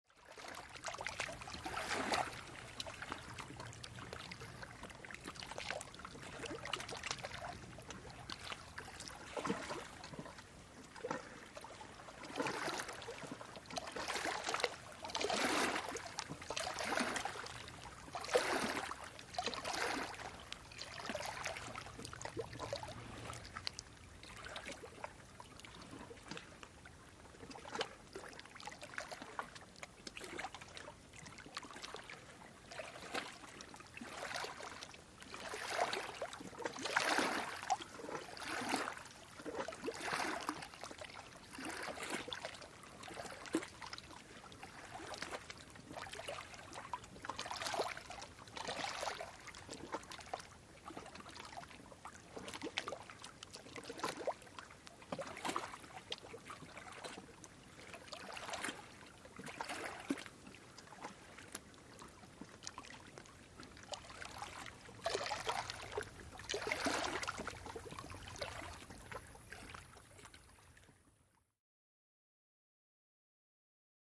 Light Steady Water Laps On A Calm Lake With Insects At Tail